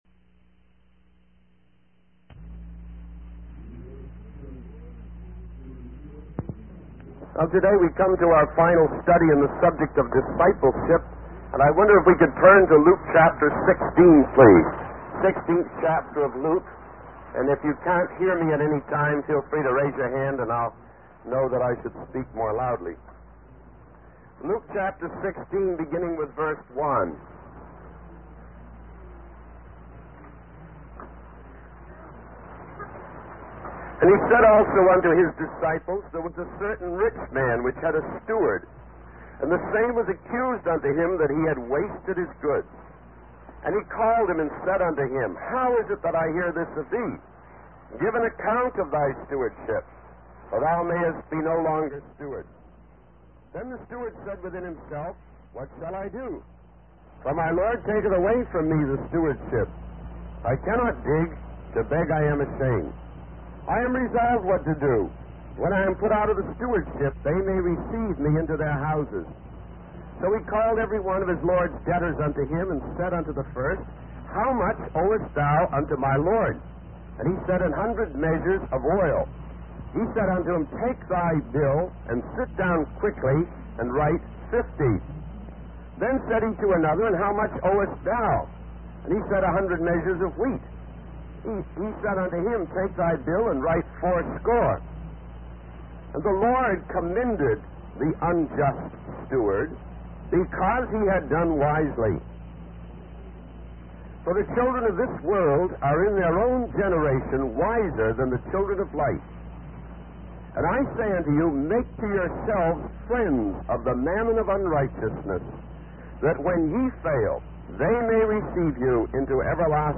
In this sermon, the preacher emphasizes the importance of living a life that is dedicated to God. He warns that it is possible for a person's soul to be saved but their life to be lost.